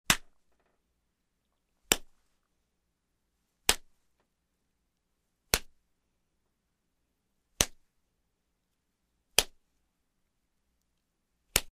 Звук пощечины
Легкие пощечины: